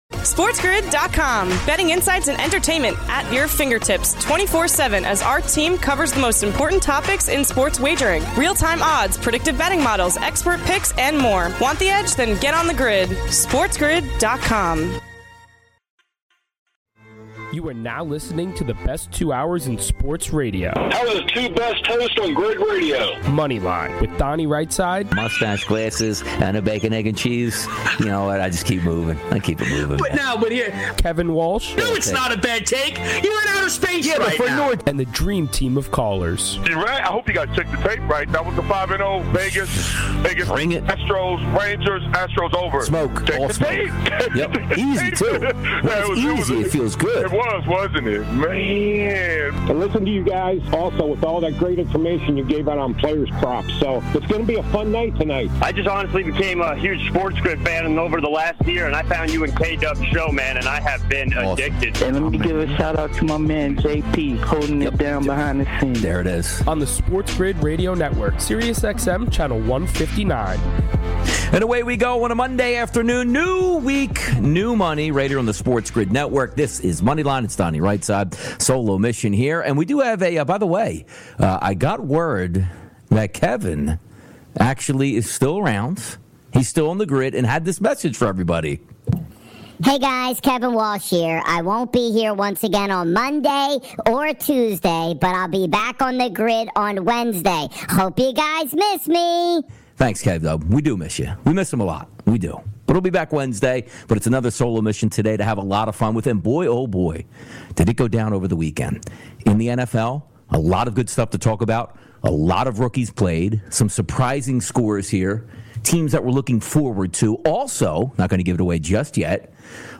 All this, your calls, his best bets, and more!